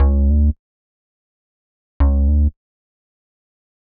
Pensive (Bass) 120BPM.wav